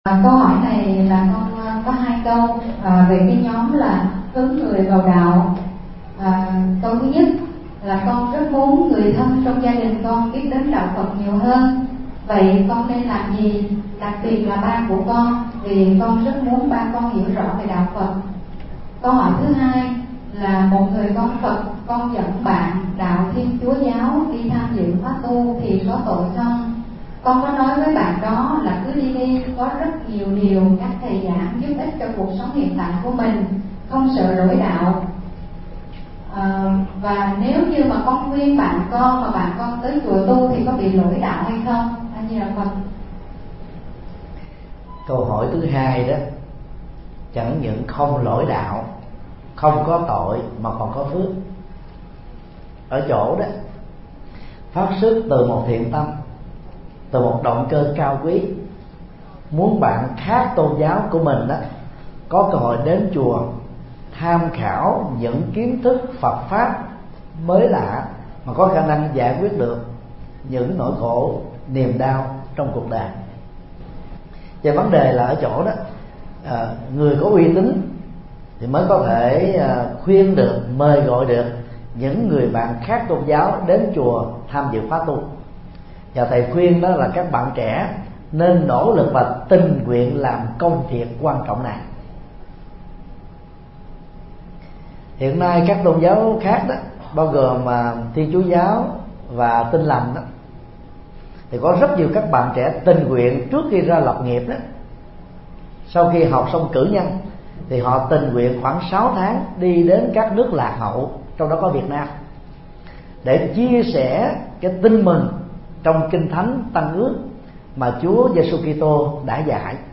Vấn đáp: Hướng dẫn người thân vào đạo – Thầy Thích Nhật Từ mp3